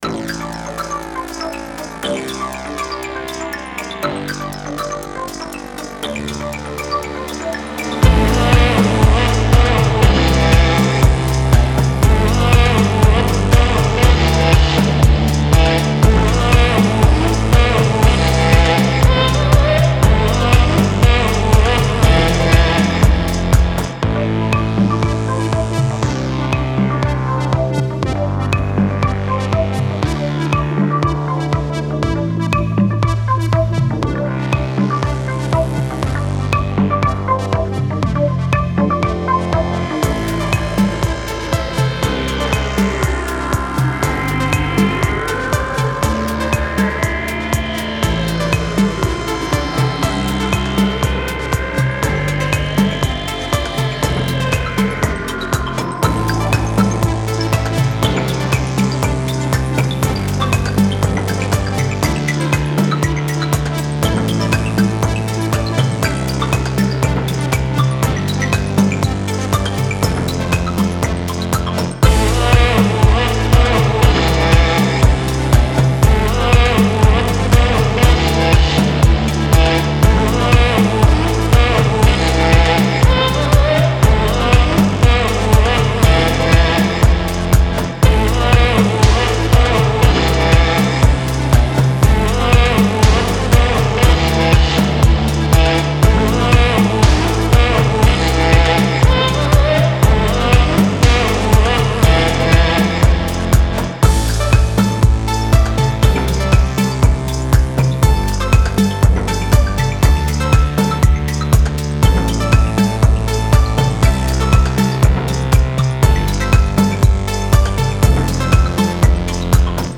Mixed